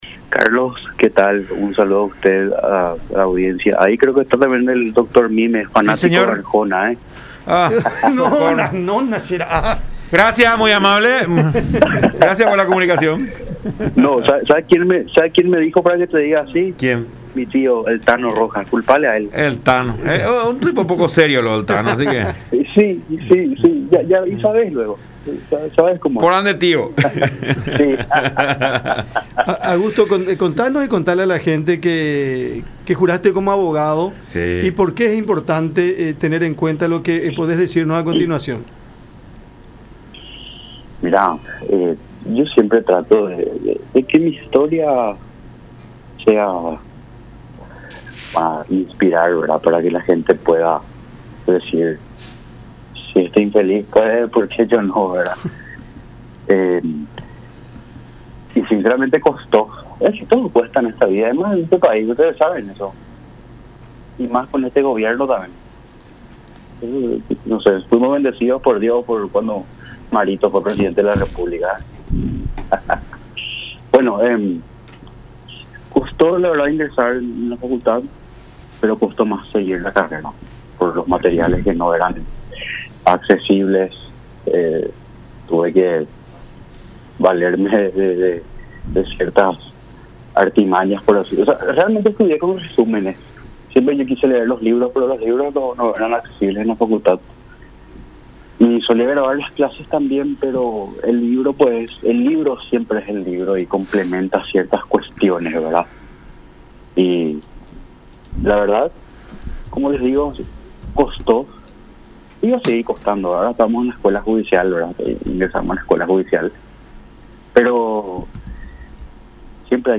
en diálogo con Cada Mañana a través de La Unión.